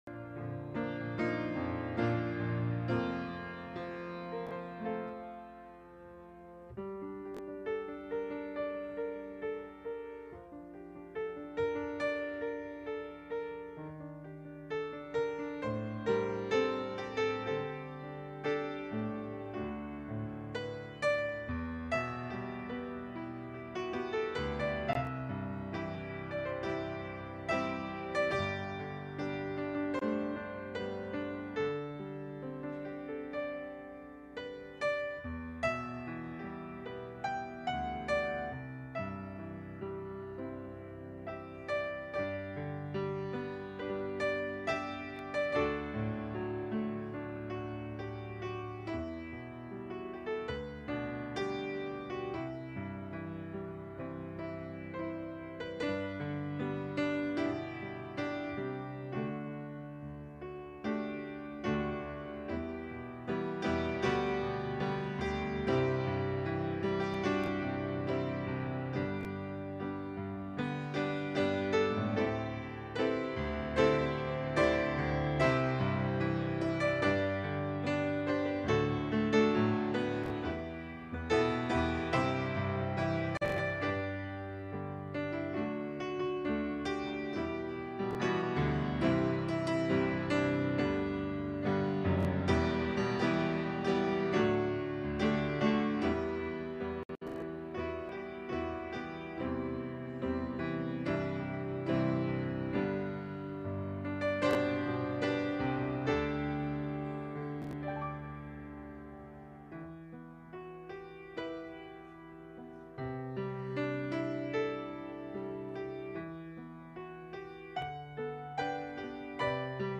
February 13, 2022 (Morning Worship)